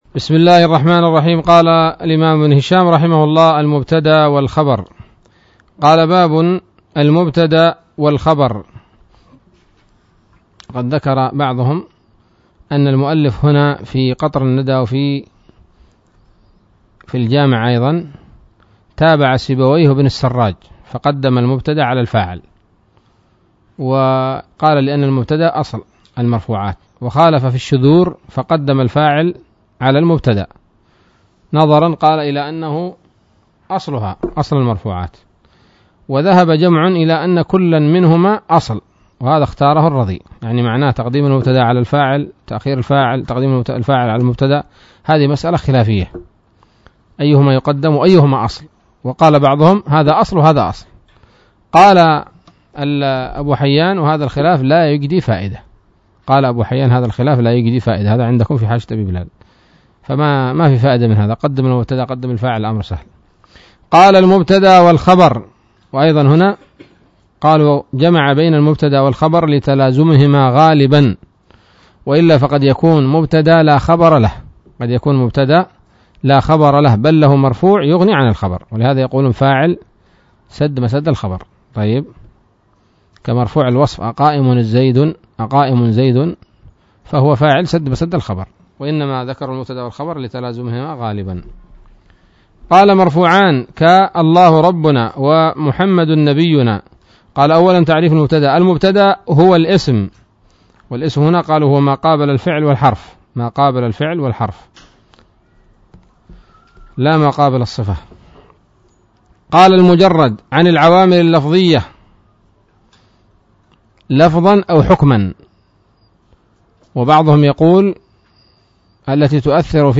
الدرس الحادي والخمسون من شرح قطر الندى وبل الصدى